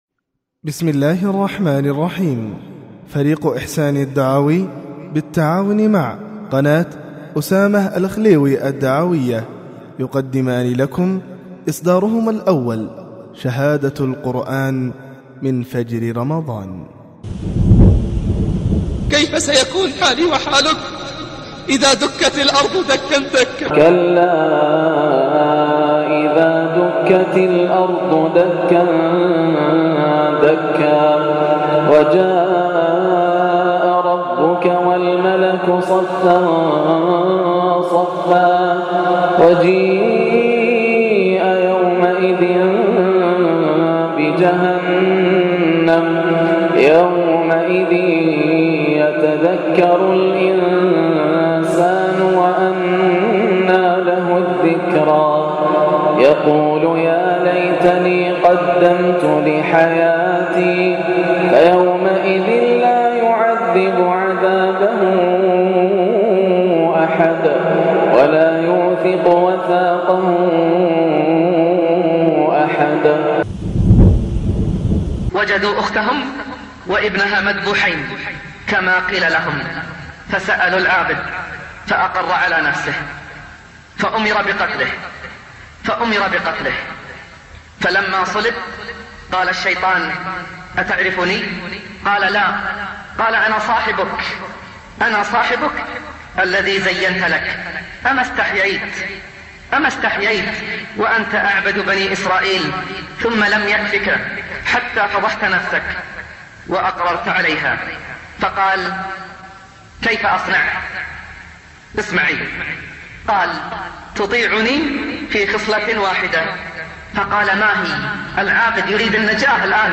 (شهادة القرآن من فجر رمضان) روائع فجريات رمضان 1434 للشيخ ياسر الدوسري > الإصدارات > المزيد - تلاوات ياسر الدوسري